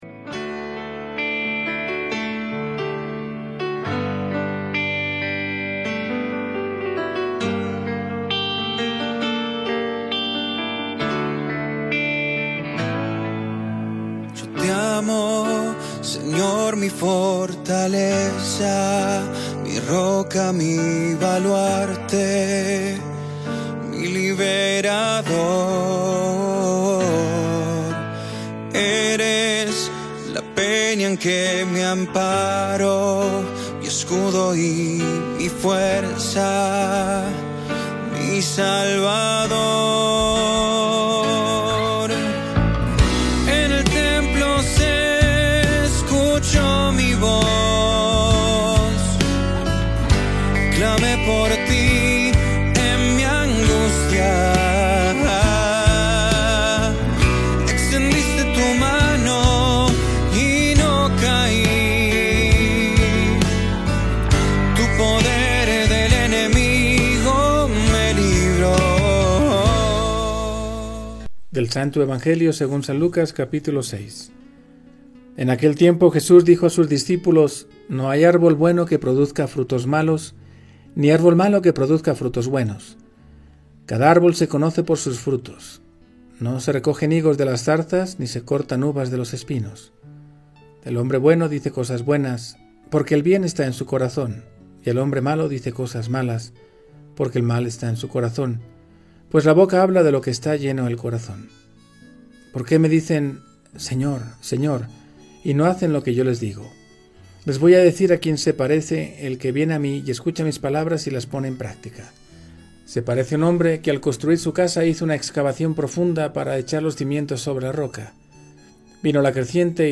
• Música: Salmo 17, Pastoral de Música RMB